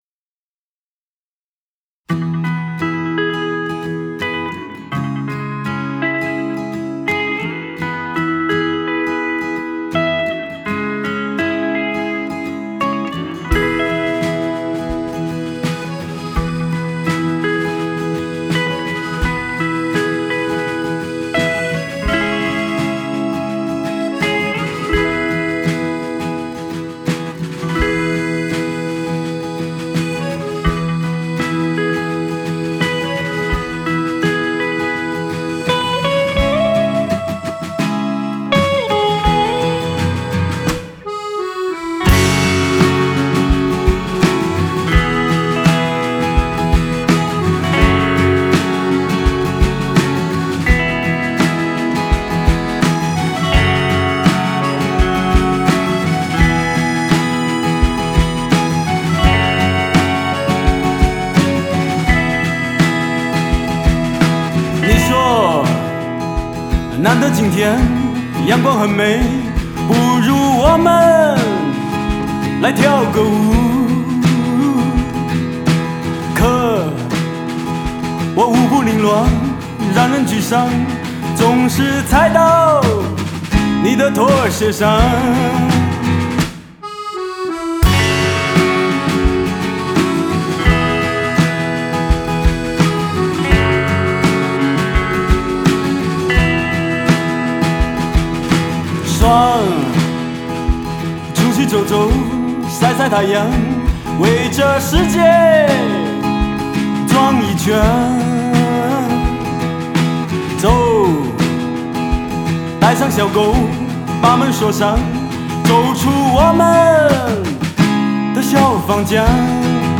Ps：在线试听为压缩音质节选，体验无损音质请下载完整版 https